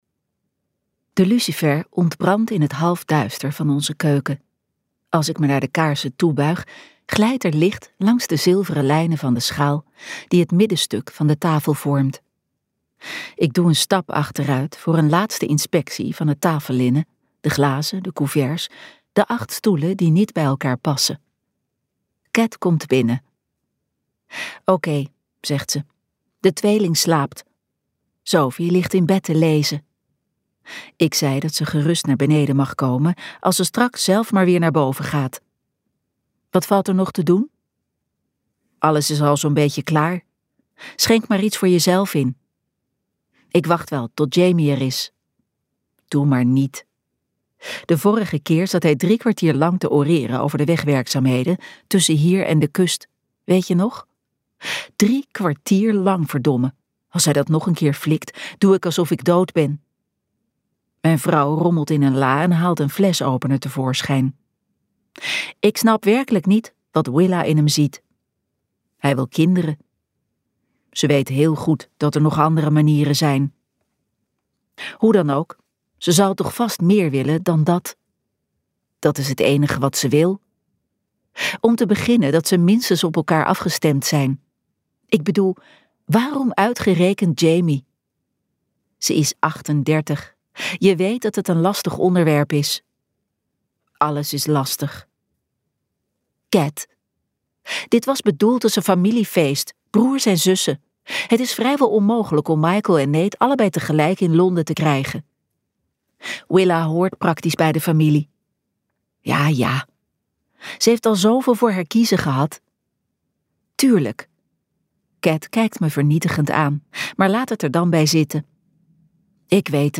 Ambo|Anthos uitgevers - Zo is het niet gegaan luisterboek